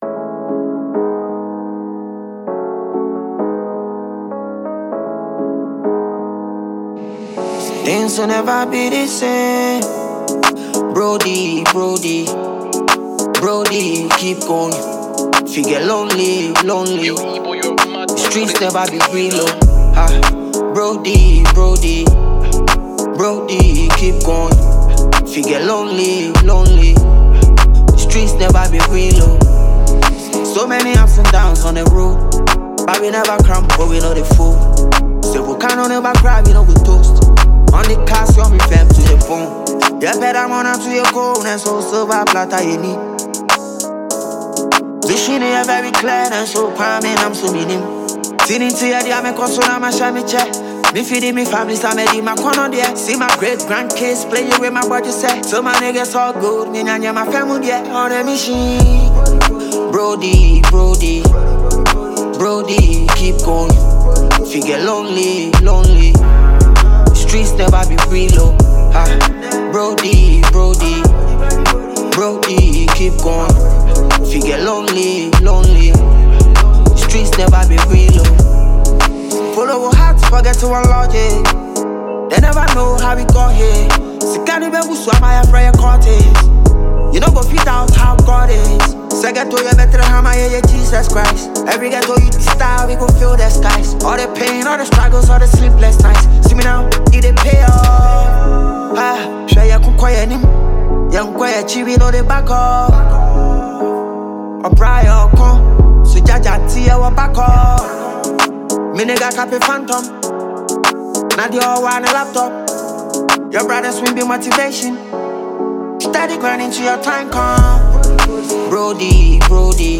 a heartfelt banger
With emotion and lyrical depth